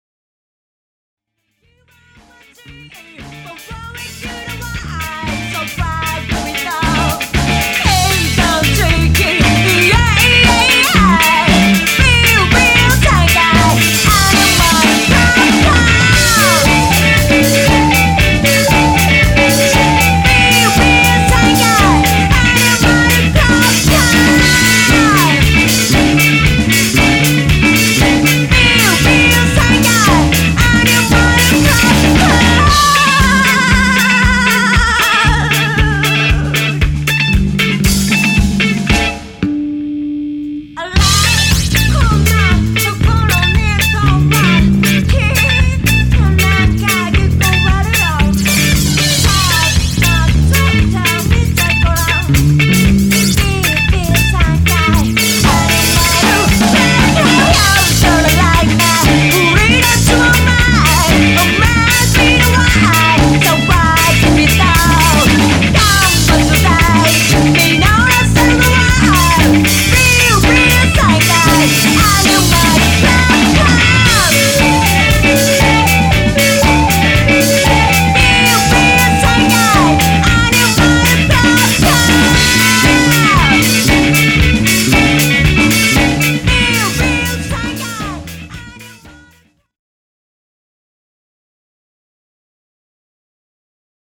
frantic, energy-packed new wave
vocals, guitar and Casio